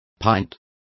Also find out how pinta is pronounced correctly.